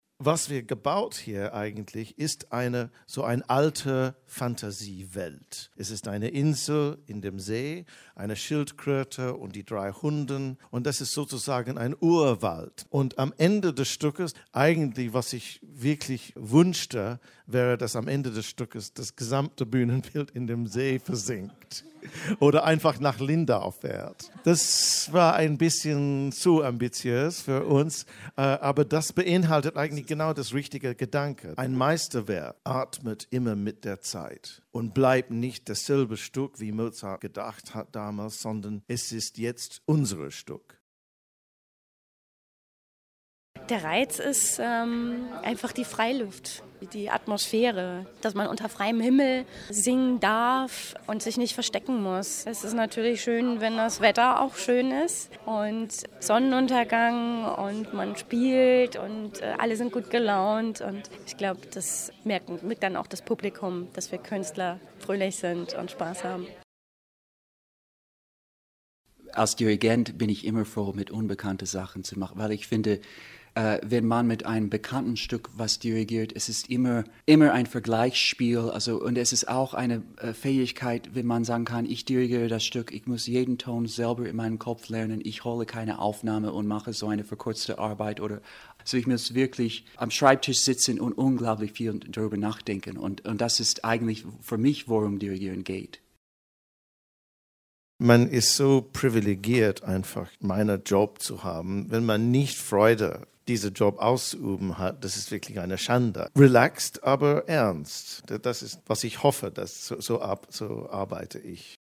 04.07.2013 Pressetag 2013 Die Bregenzer Festspiele luden zum Pressetag.
O-Töne vom Pressetag 2013 - Newsbeitrag